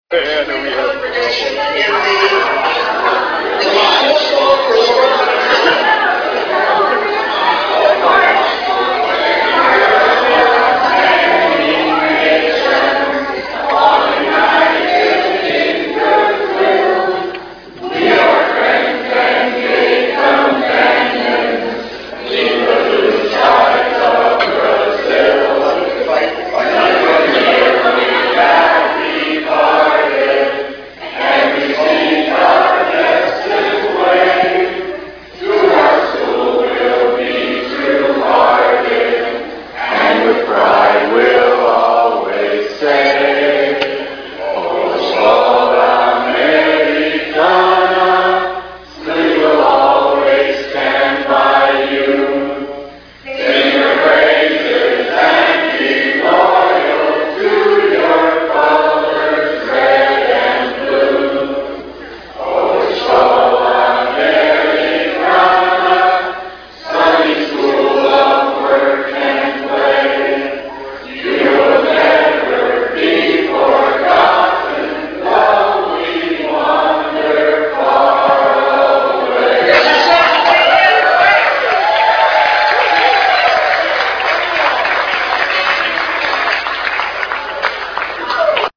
leads EA alumni in song from the Phoenix Reunion!  It took everyone a minute to get started, so hang with them.